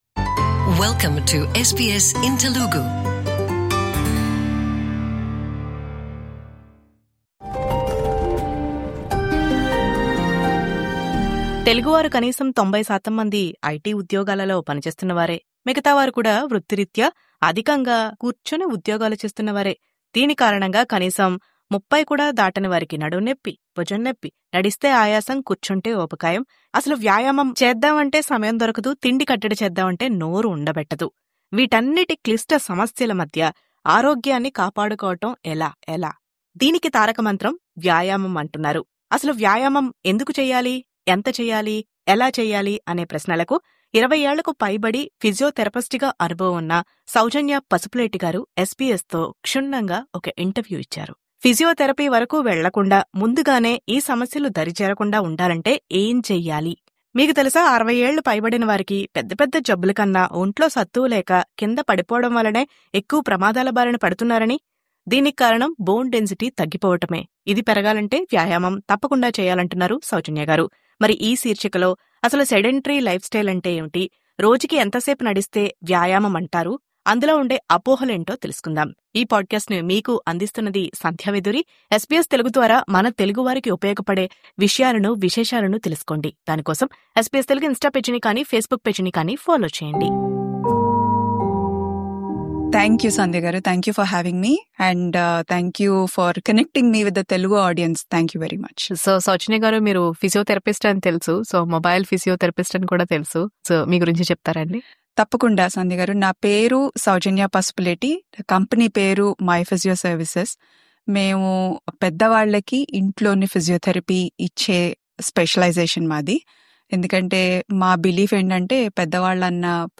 ఈ గోల్డెన్ రూల్స్ తప్పక పాటించాలి.. 21:31 In this interview